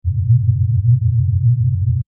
Alien Spaceship Hum 03
Alien_spaceship_hum_03.mp3